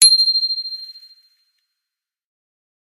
bicycle-bell_13
bell bicycle bike clang contact ding glock glockenspiel sound effect free sound royalty free Sound Effects